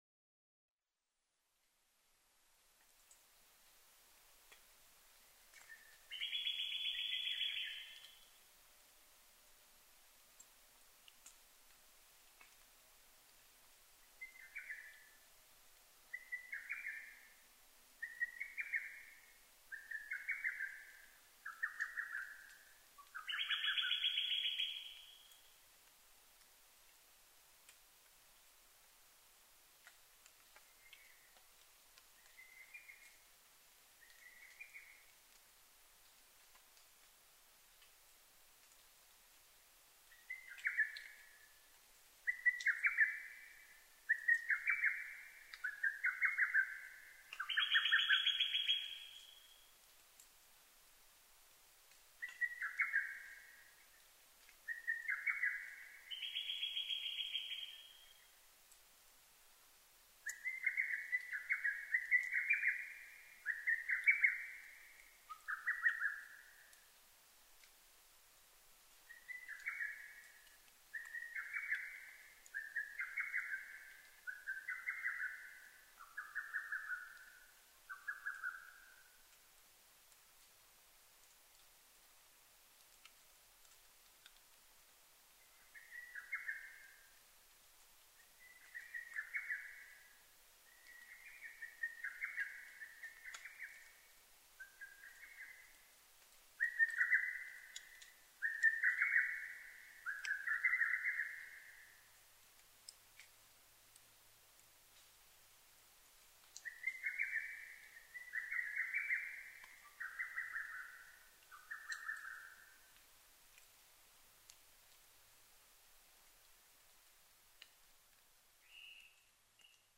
ホトトギス　Cuculus poliocephalusカッコウ科
日光市湯西川　alt=1180m
Mic: Panasonic WM-61A  Binaural Souce with Dummy Head
落葉樹の深い森。まだ他の鳥たちがさえずり始める前の黎明の時間。二羽のホトトギスが鳴き合っていた。